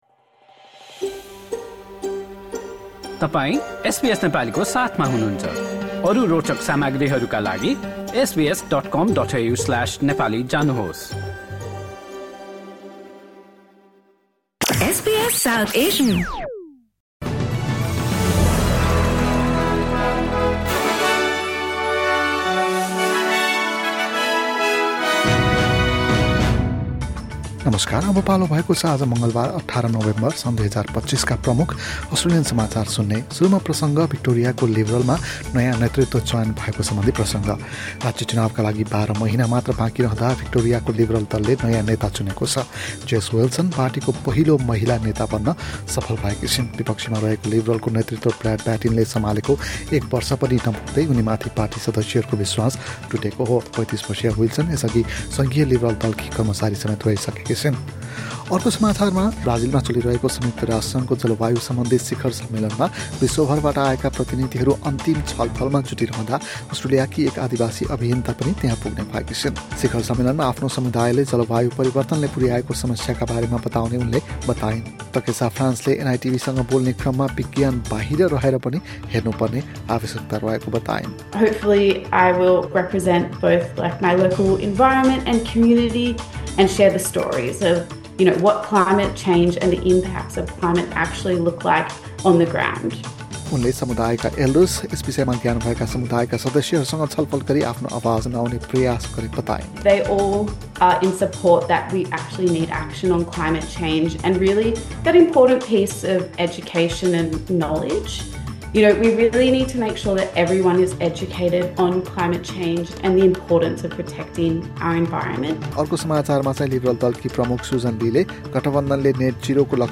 एसबीएस नेपाली प्रमुख अस्ट्रेलियन समाचार: मङ्गलवार, १८ नोभेम्बर २०२५